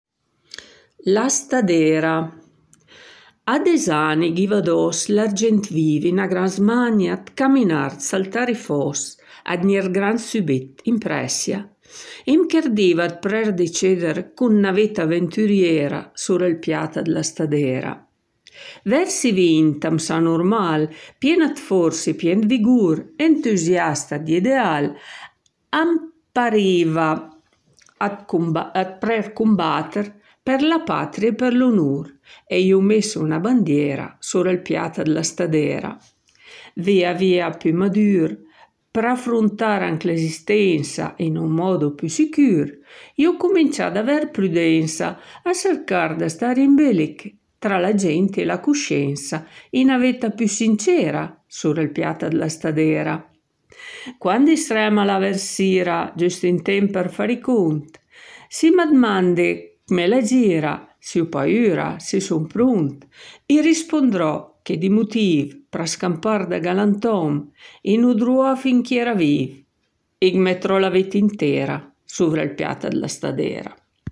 La poesia La stadêra è letta